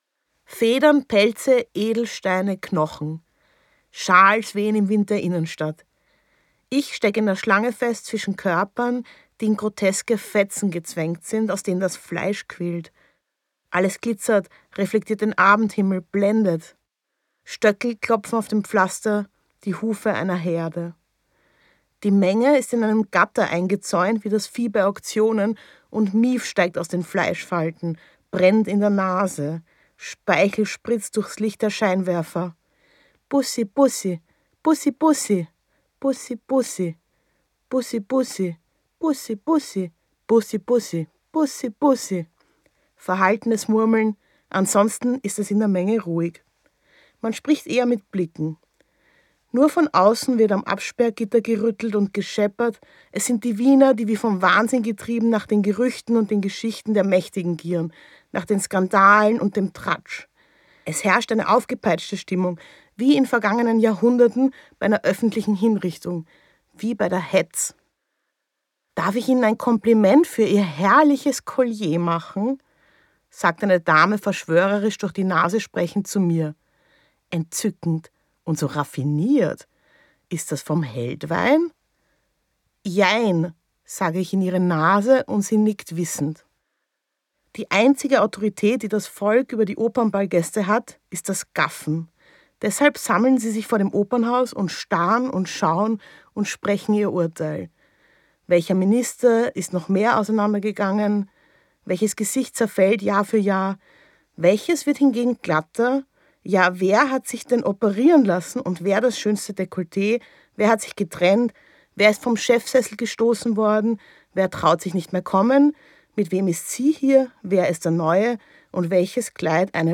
Stefanie Sargnagels selbst gelesenen Hörbücher sind unnachahmliche, skurrile Hörvergnügen.
Gekürzt Autorisierte, d.h. von Autor:innen und / oder Verlagen freigegebene, bearbeitete Fassung.
Opernball Gelesen von: Stefanie Sargnagel